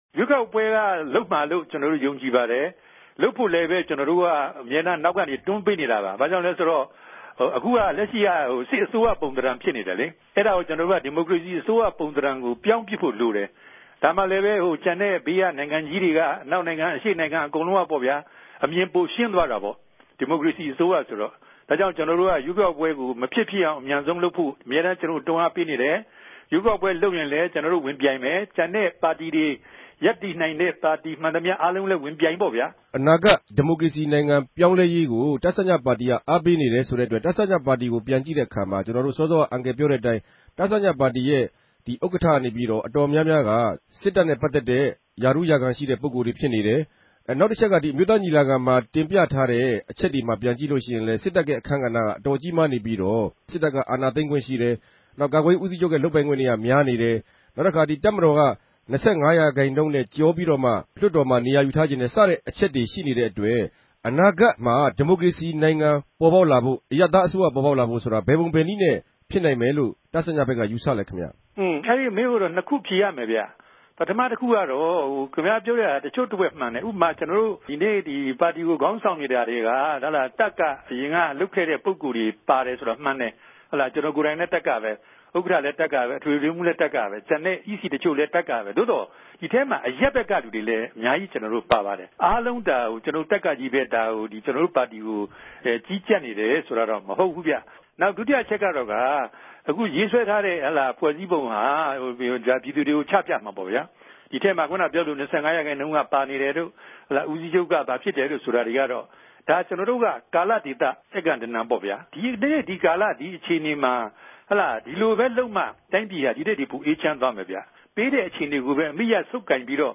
ဗန်ကောက် RFA ႟ုံးခြဲကနေ ဆက်သြယ်မေးူမန်းထားပၝတယ်။